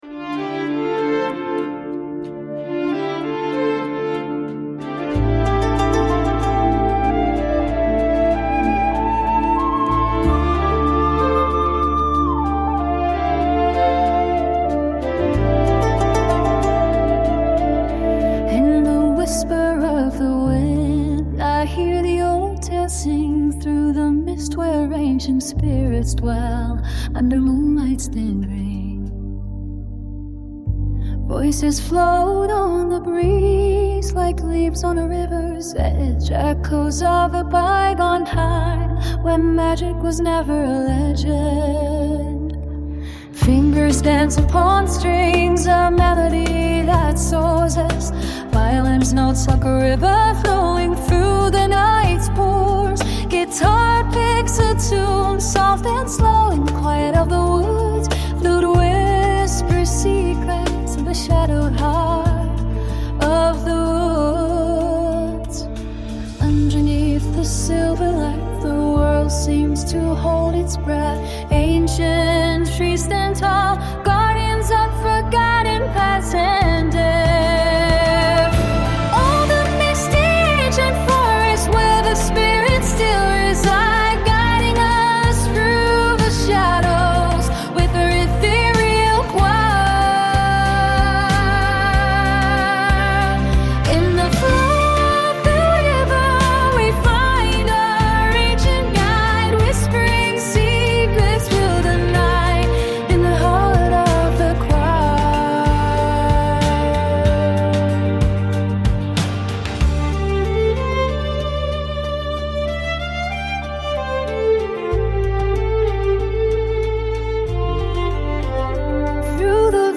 Optional background music for a gentle reading atmosphere